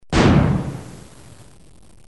دانلود صدای بمب و موشک 17 از ساعد نیوز با لینک مستقیم و کیفیت بالا
جلوه های صوتی